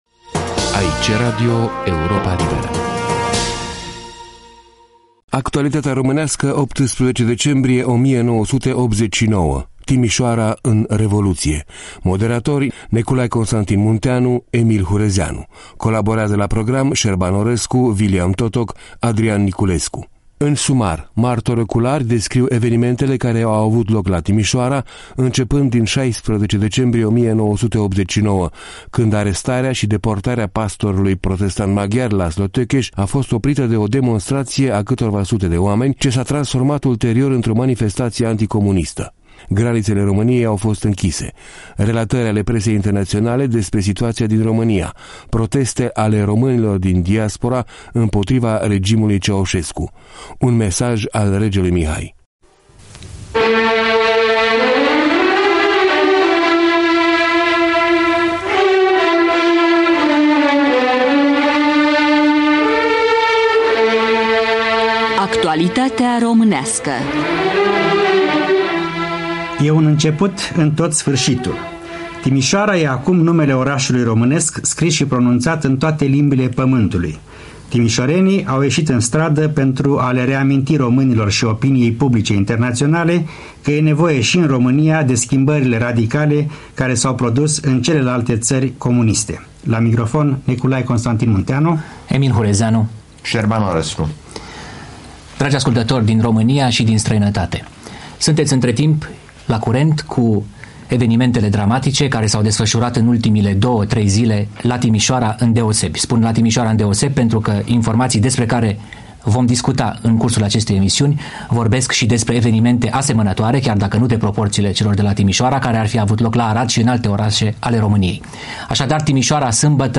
Moderatori: Neculai Constantin Munteanu, Emil Hurezeanu; colaborează la program